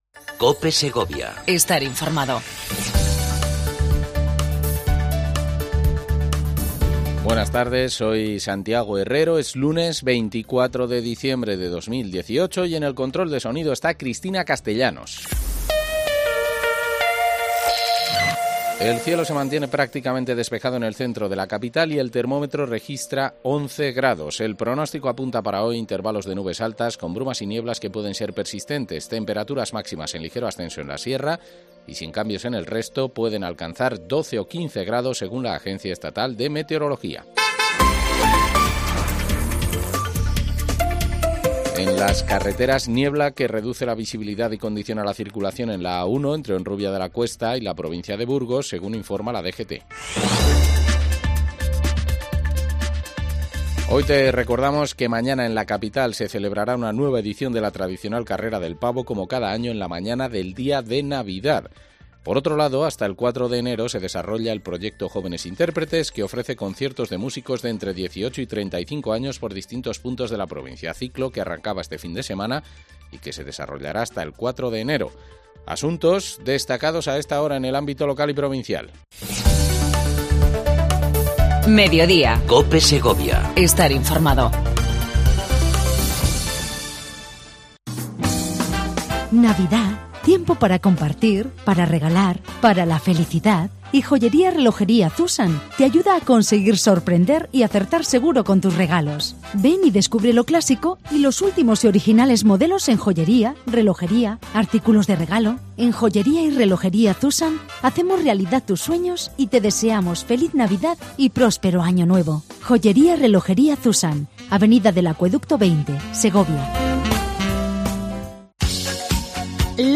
AUDIO: Entrevista mensual a Lirio Martín, Subdelegada del Gobierno en Segovia